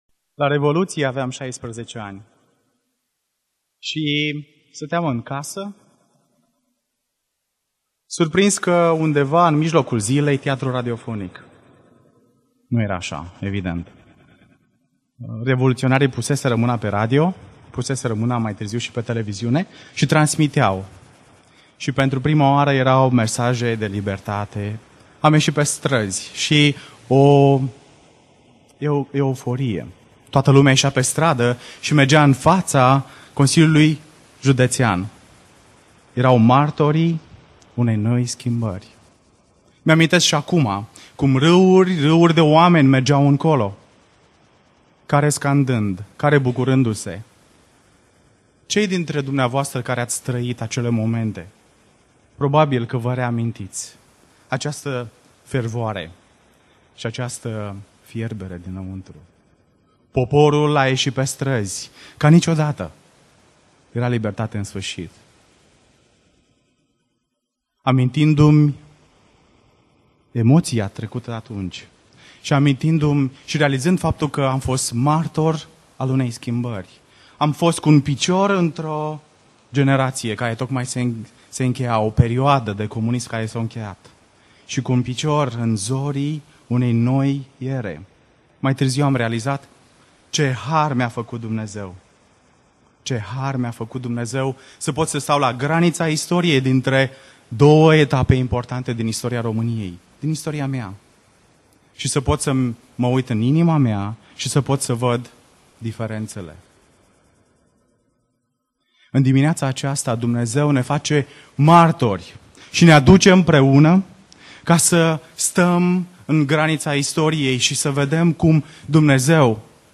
Predica Exegeza - 1 Imparati Cap 1-2